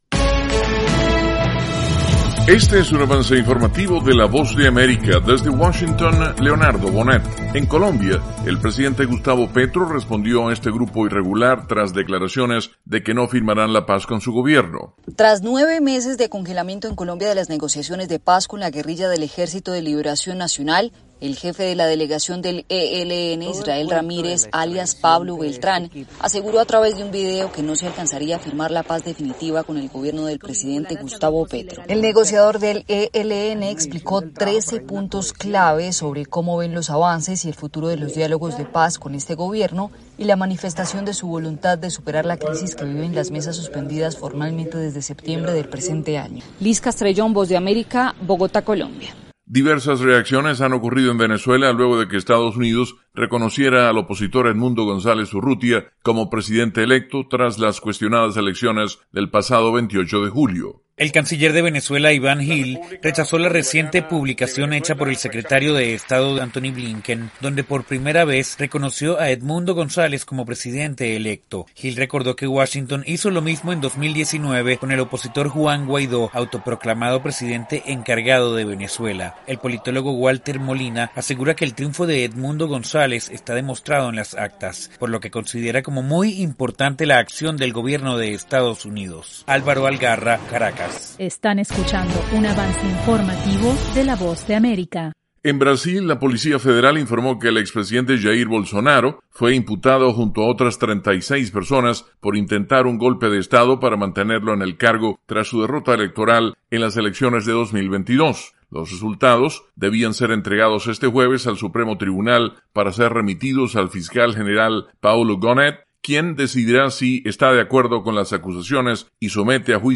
Última hora